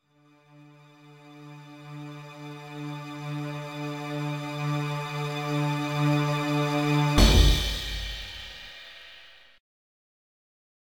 Countdown music